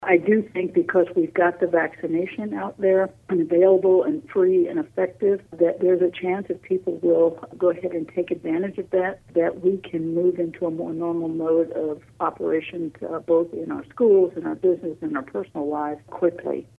Governor Laura Kelly spoke with News Radio KMAN this week during an exclusive interview heard Wednesday on KMAN’s In Focus.